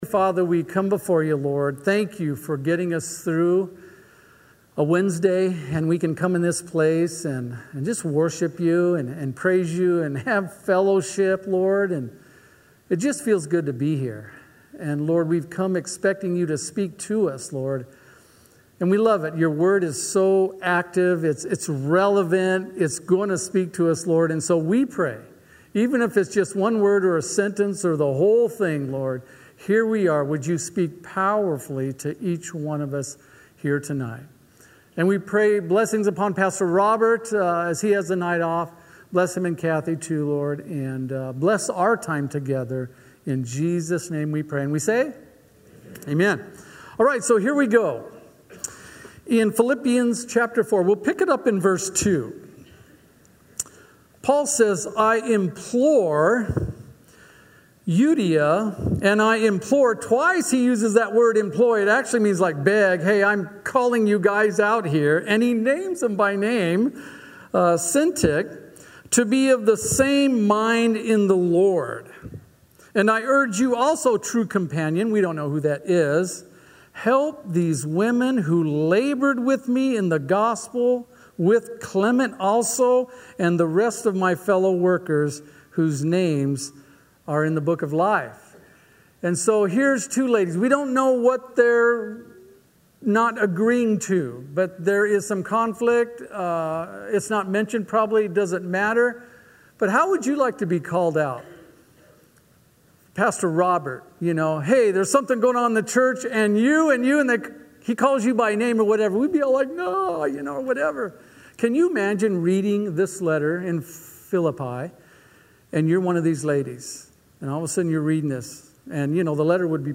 Listen to guest speaker